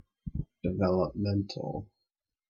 Ääntäminen
Ääntäminen : IPA : /dɪˌvɛləpˈmɛntəl/ Haettu sana löytyi näillä lähdekielillä: englanti Käännöksiä ei löytynyt valitulle kohdekielelle.